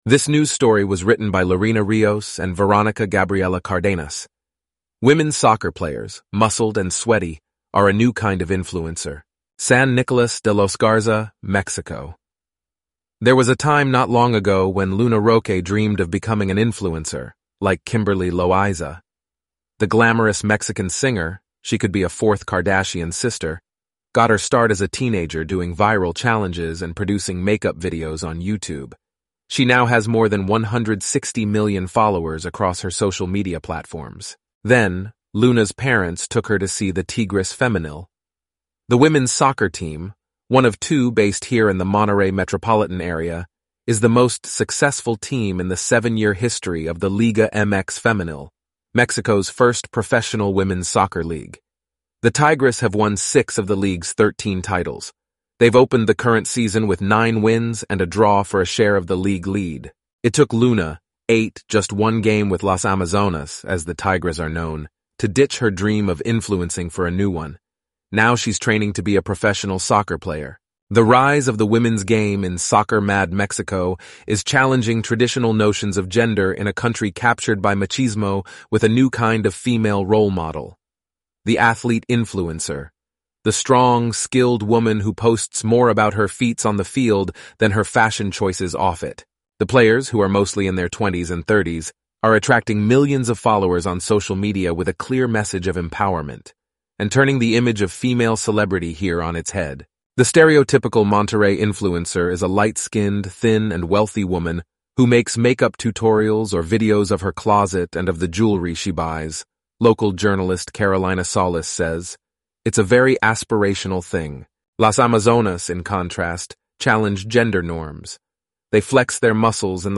eleven-labs_en-US_Antoni_standard_audio.mp3